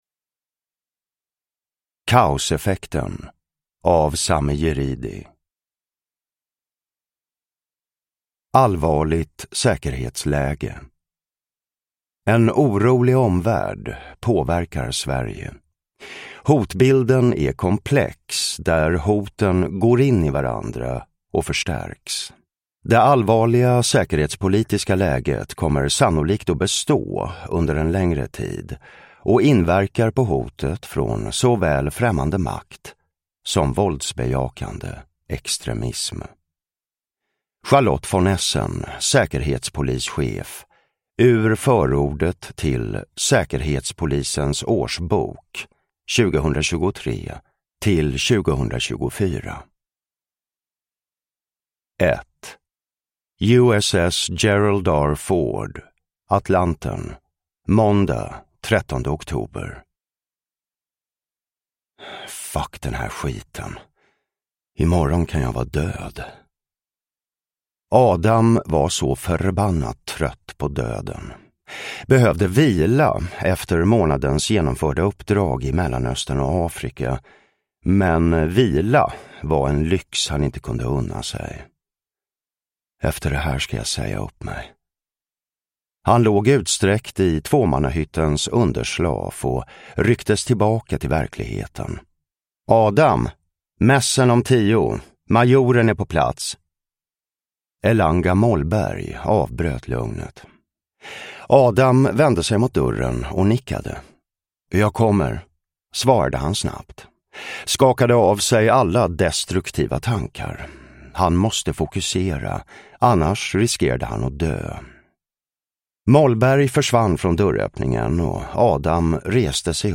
Kaoseffekten – Ljudbok
Uppläsare: Jonas Malmsjö